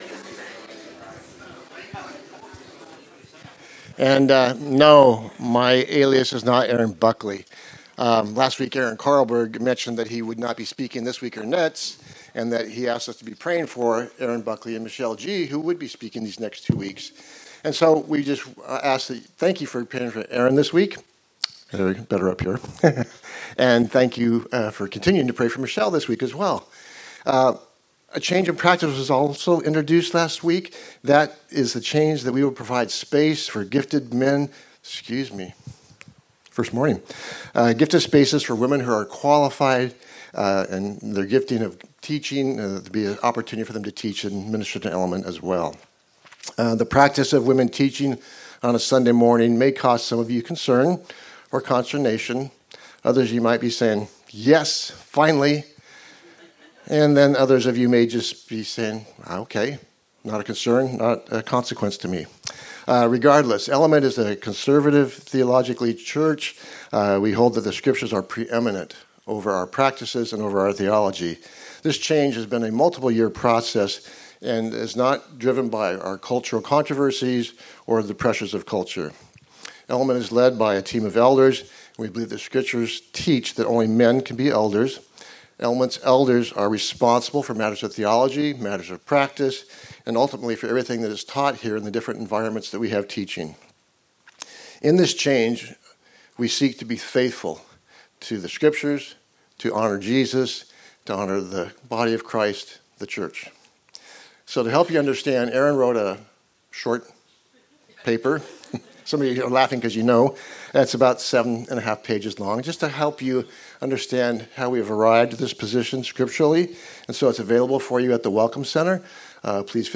Service Audio We know Christmas was last week, but we are still working through our series on “The Carols.”